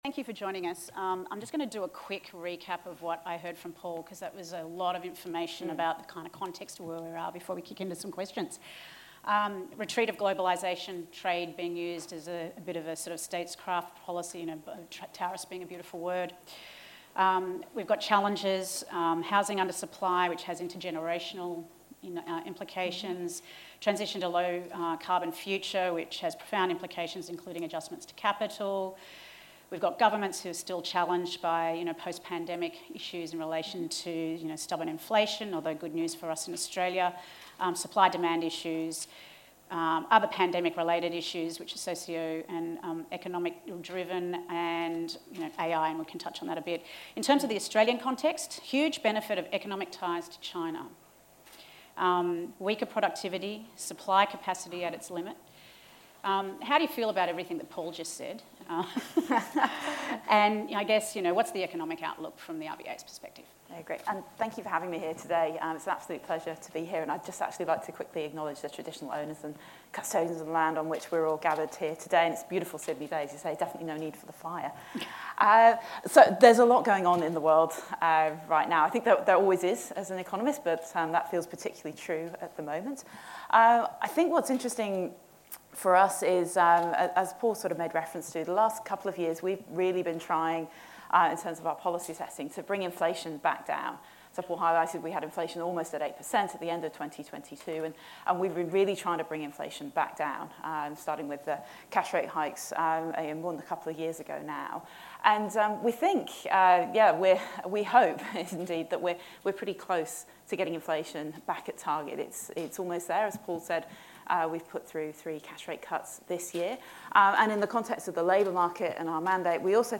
Opening in a new window, to the downloadable audio file of Sarah Hunter’s speech given on 3 June 2025.
In this speech to the Economic Society of Australia (Queensland), Sarah Hunter, Assistant Governor (Economic), explores the interconnectedness of global and domestic economies, and outlines the RBA’s analytical framework for navigating economic uncertainty and informing monetary policy decisions.